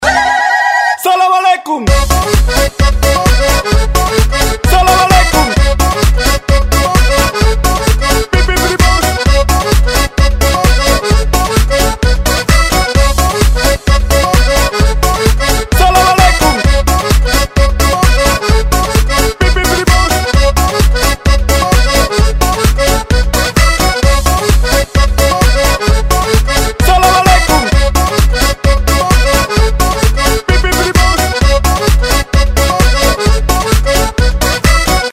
• Качество: 128, Stereo
забавные
веселые